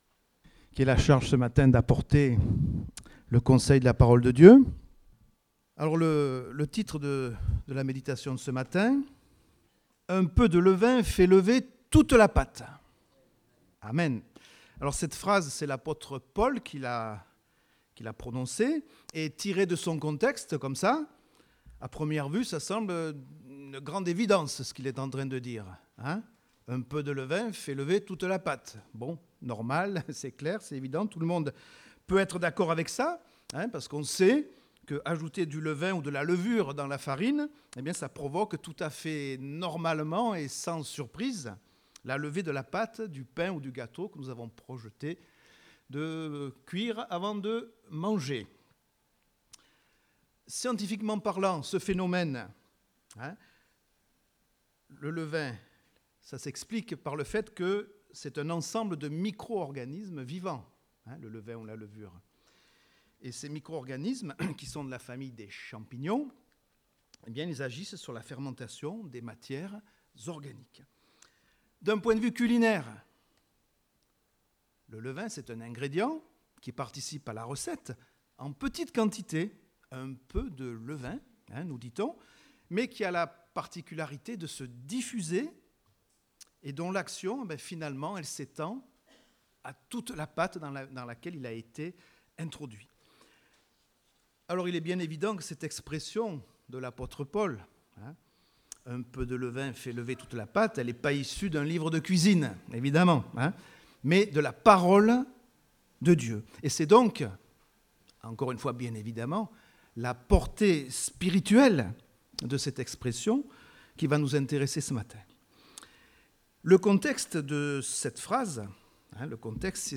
Date : 1 juillet 2018 (Culte Dominical)